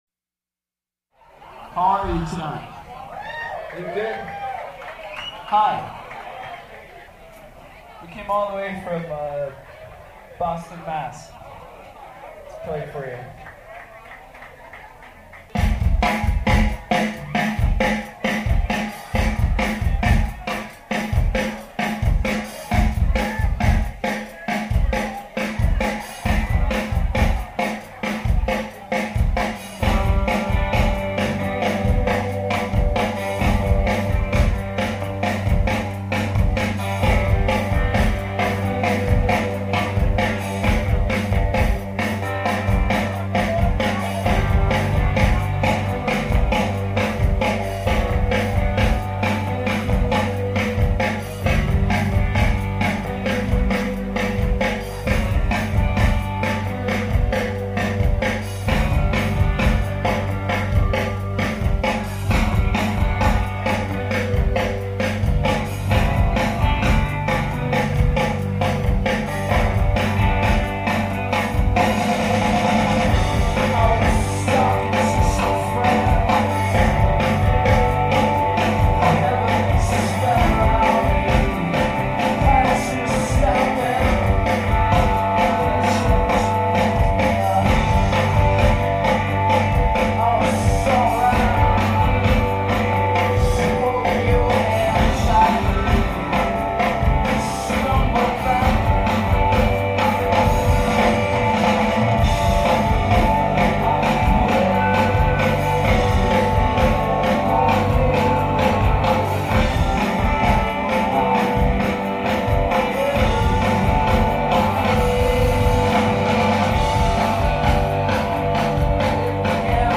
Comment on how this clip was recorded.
schuba's chicago september 2000